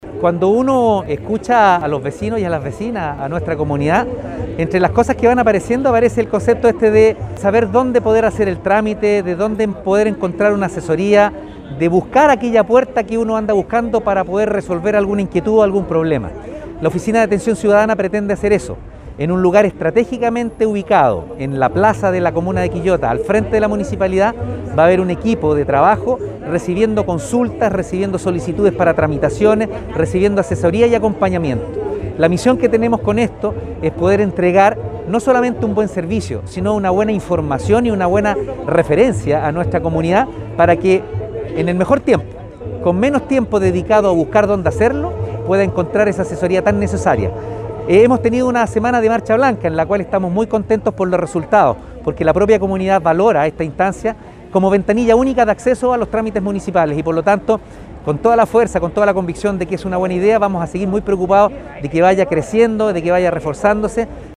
Alcalde Oscar Calderón Sánchez junto a integrantes del Concejo Municipal realizaron la inauguración oficial de esta nueva iniciativa de la actual administración local
Alcalde-Oscar-Calderon-Sanchez-1.mp3